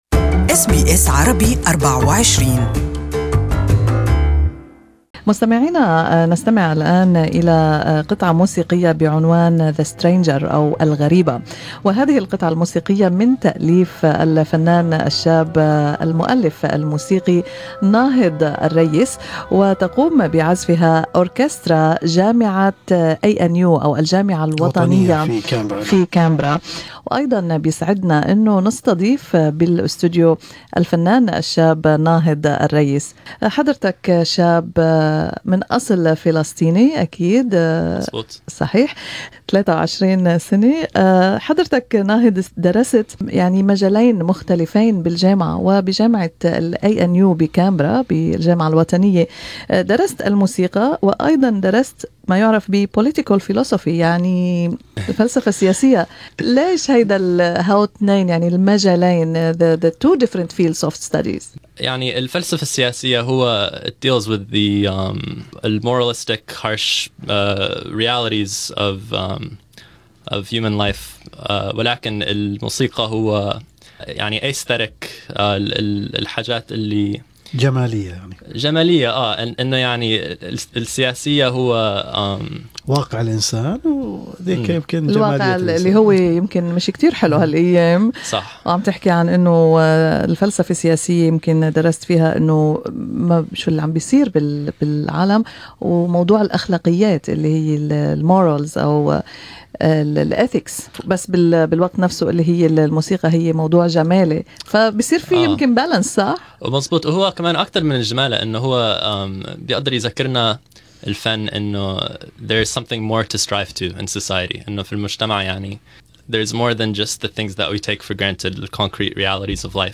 speaks to SBS Arabic24 about his music endeavors and what it means for him to play, compose and teach music.